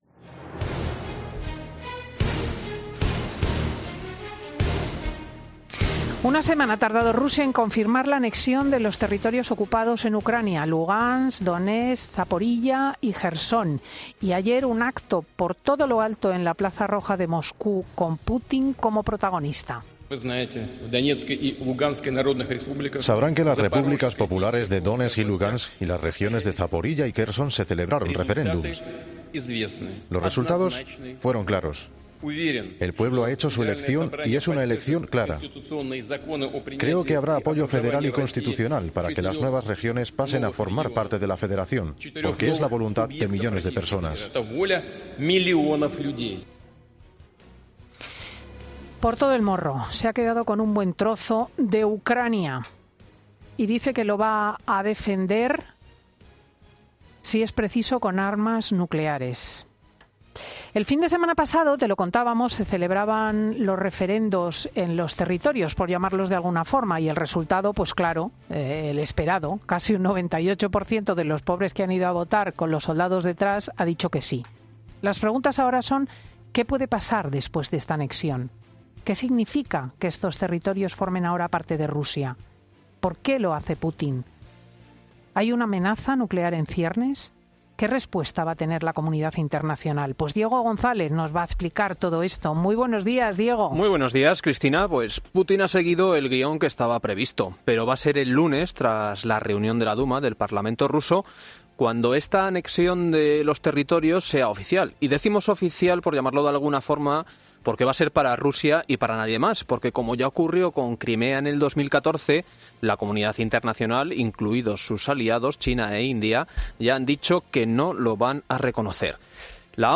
En Fin de Semana hemos querido recopilar unos cuantos testimonios de expertos y de rusos para que nos alumbren acerca de todo lo que está pasando en Rusia y Ucrania.